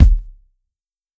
LL_kick_luger.wav